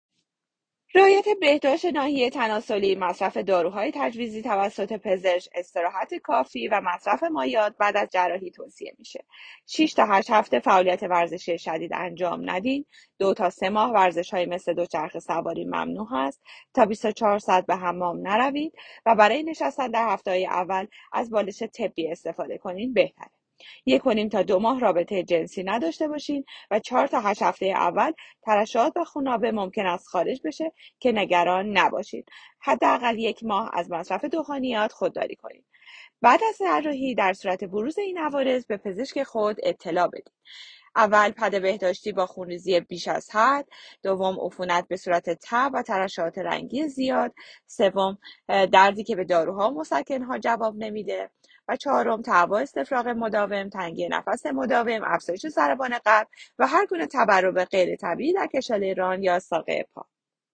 مشاوره صوتی